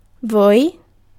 Ääntäminen
IPA: /vu/